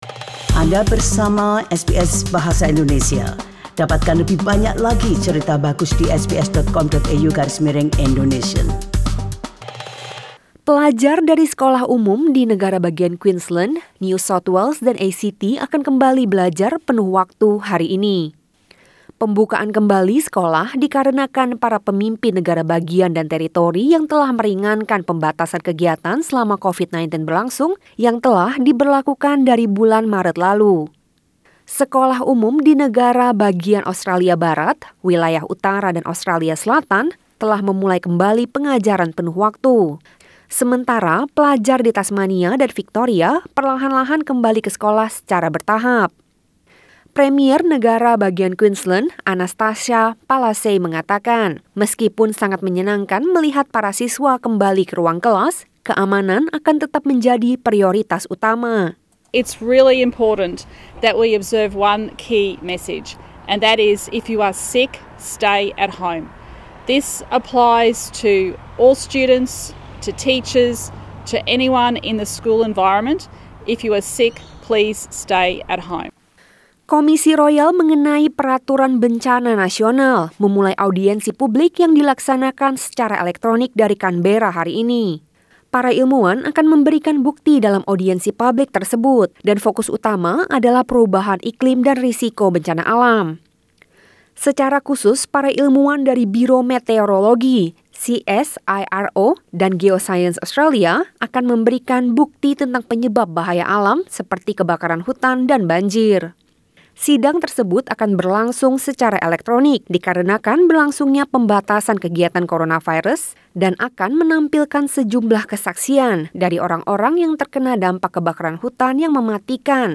SBS Radio News in bahasa Indonesia - 25 May 2020